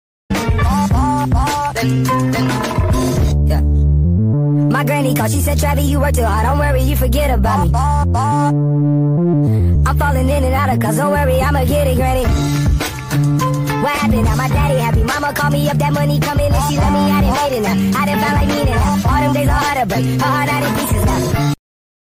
Ball Ball Ball meme sound effect